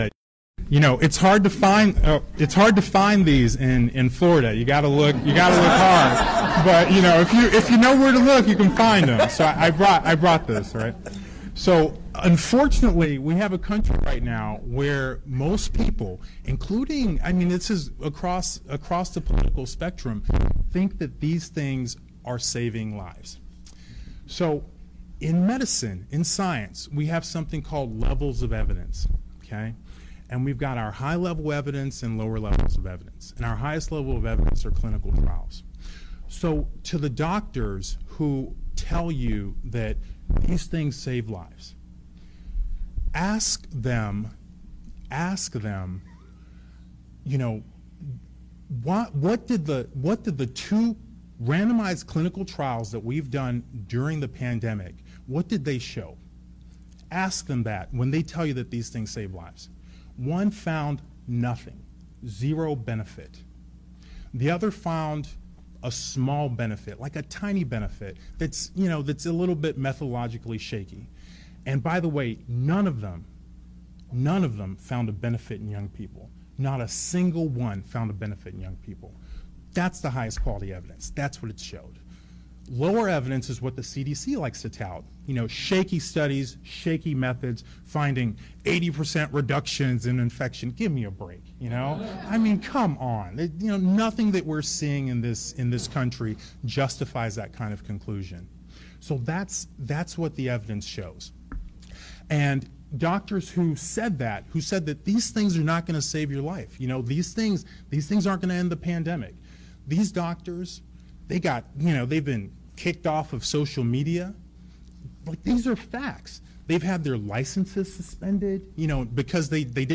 Der Generalchirurg von Florida, Dr. Joseph Ladapo, in einer Rede zum Maskenwahnsinn des Narrativs, Masken würden Leben retten - in Zusammenhang mit Covid-19... aber was weiss schon ein Chirurg, ist ja schliesslich kein Epidemiologie....